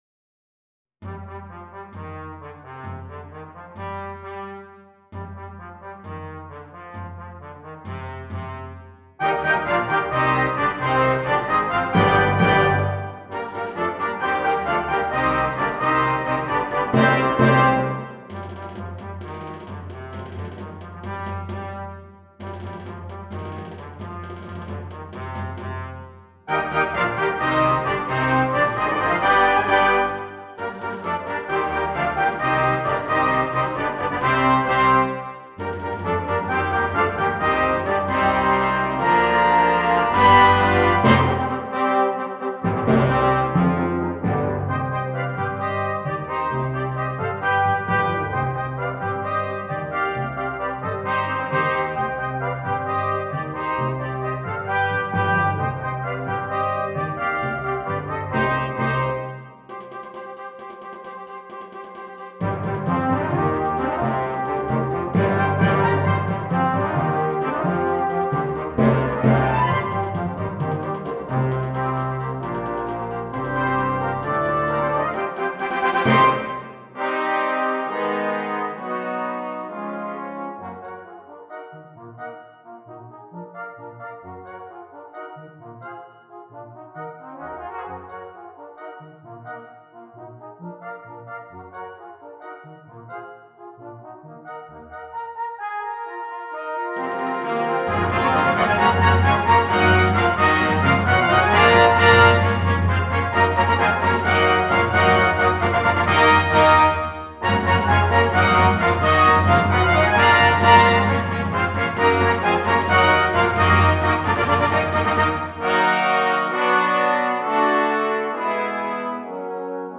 Brass Band
Traditional Spanish Carol